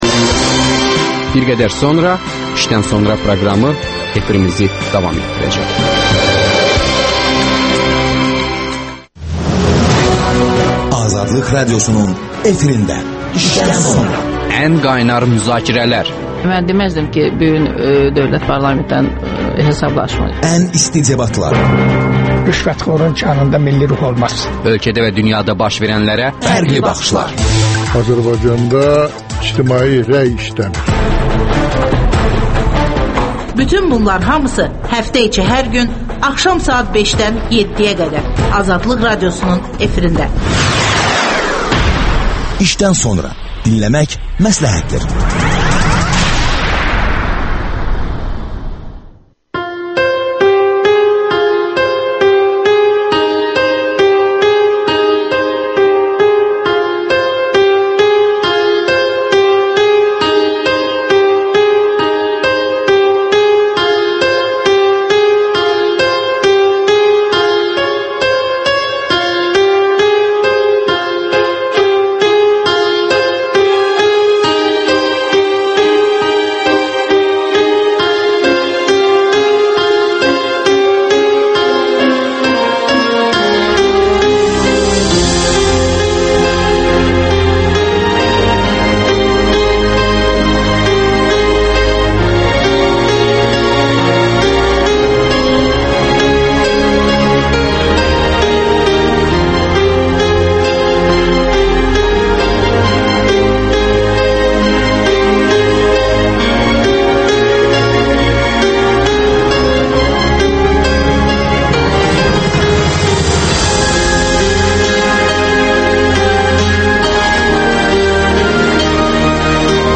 Siyasi şərhçilər